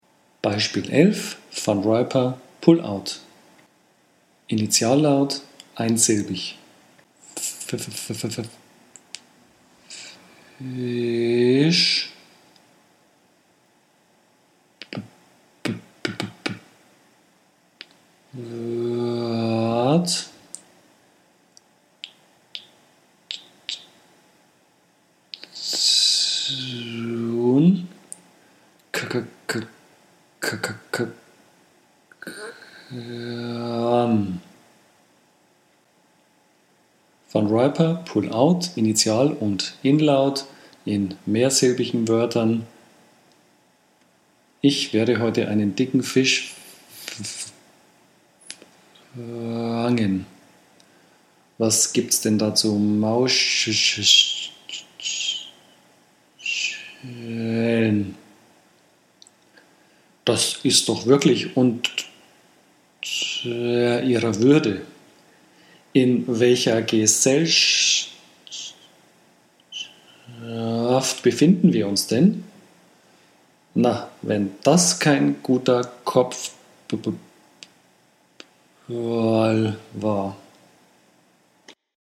Sprechtechniken in der Stottertherapie. Übersicht über gängige Sprechtechniken mit Audio-Beispielen
Soundbeispiel 11 (Van Riper: Pull-out)
11_Van_Riper_Pull-Out.mp3